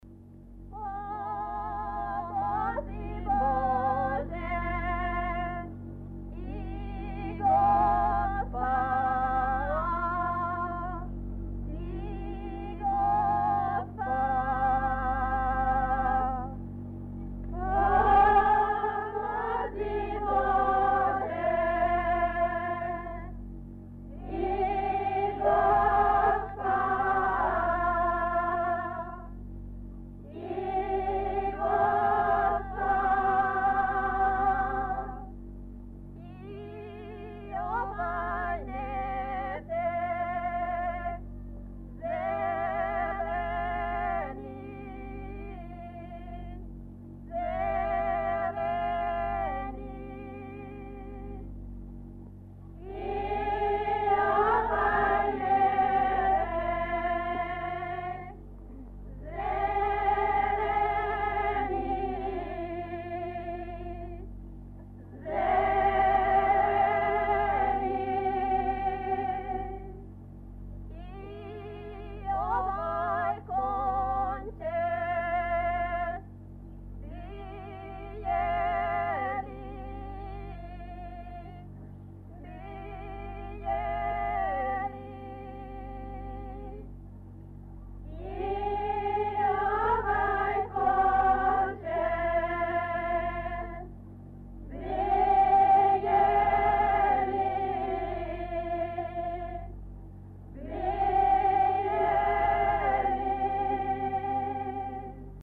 Mesto: Lukovišće
Napomena: Pevale su dve grupe žena na bas, antifono. Melodija vezana za vezivanje zimzelenog venca.
Oba dva su jedna melodija, ujedno se pevaju na snimku.